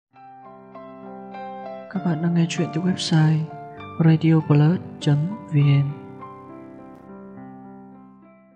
Sách nói | Bác Sĩ Trưởng Khoa